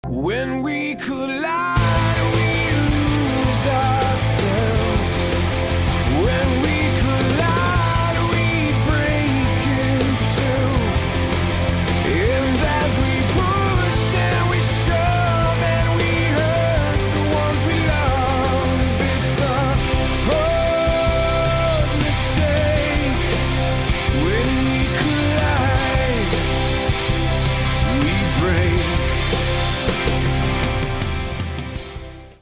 • Качество: 128, Stereo
мелодичные
Alternative Rock
красивый мужской вокал
grunge